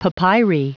Prononciation du mot papyri en anglais (fichier audio)
Prononciation du mot : papyri